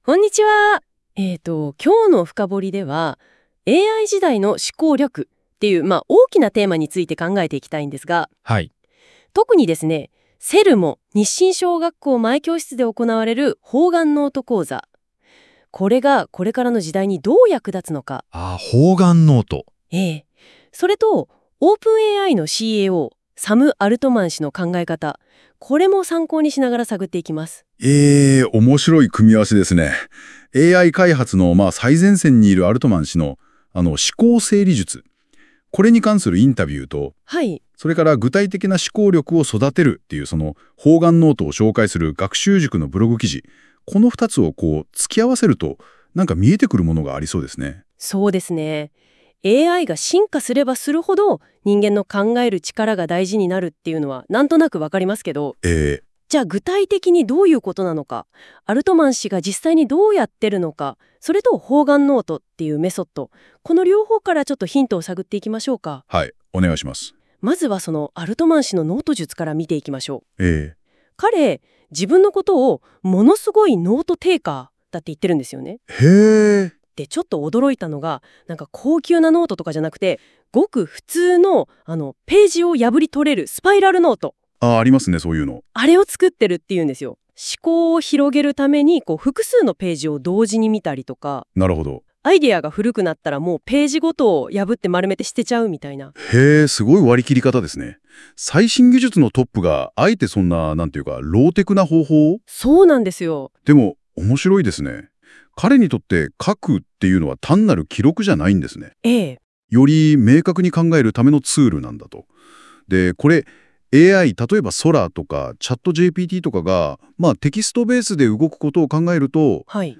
いつものようにＡＩに音声解説を作ってもらったので、聞いてみてください！